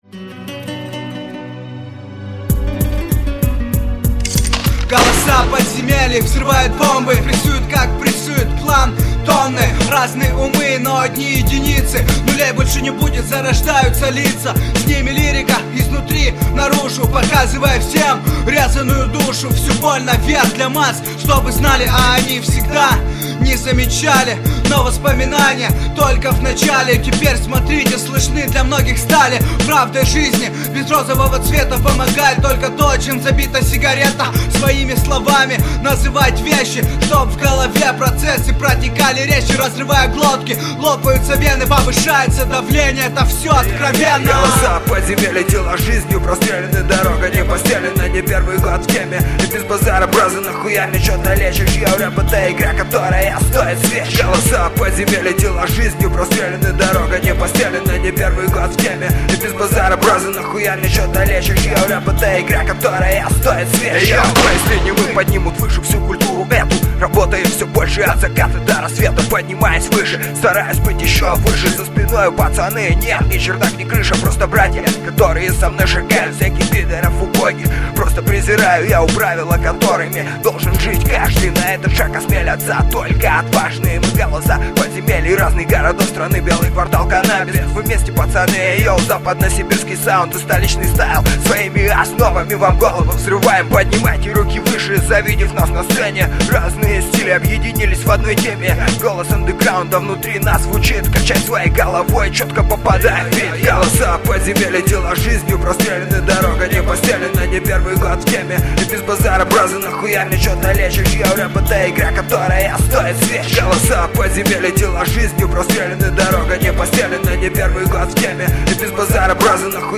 mp3,2554k] Рэп